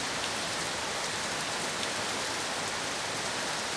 rain_heavy.ogg